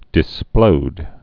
(dĭ-splōd)